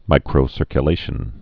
(mīkrō-sûr-kyə-lāshən)